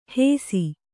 ♪ hēsi